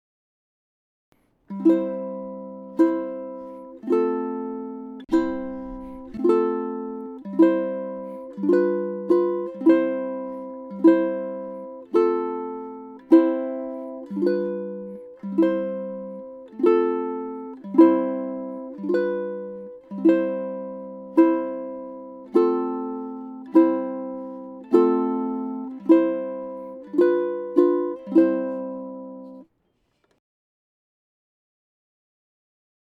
• Strum downwards on beats 1 and 3.
Down 2X Strum | ʻImo ʻImo chords strummed twice per measure (no vamp).